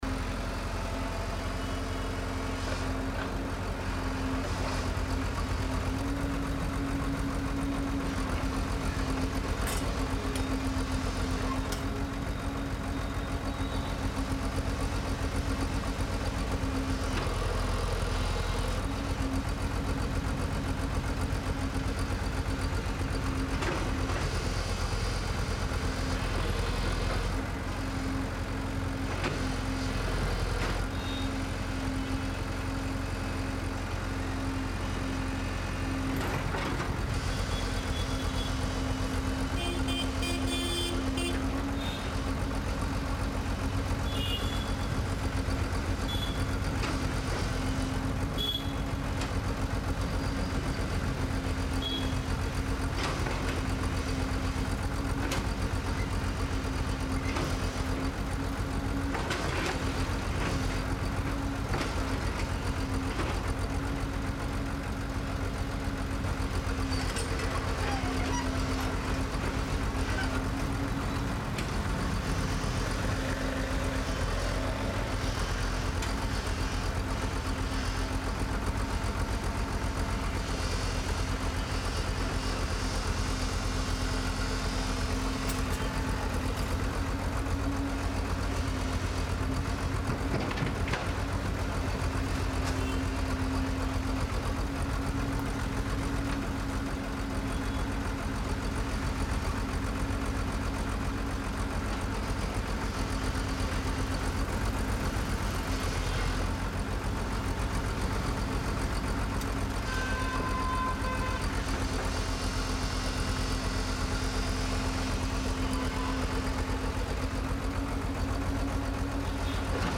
Road Work-AMB-035
Road Construction ambience captures the intense, gritty atmosphere of an active roadway work zone. Featuring layered sounds of drilling, asphalt cutting, heavy machinery, grinding metal, distant hammering, and workers’ minimal movement, this ambience delivers a realistic urban construction environment. The mix highlights mechanical texture, rough industrial pressure, and continuous working rhythm—perfect for adding authenticity, tension, or urban realism to any scene. Clean outdoor recording ensures a balanced ambience that complements visuals without overpowering dialogue or main audio.
Road Repair / Machinery
High Mechanical
Outdoor Urban Field
Thirtyfive-road-construction.mp3